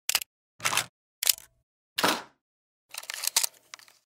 Звуки экшн-камеры
Звук клика кнопки экшн-камеры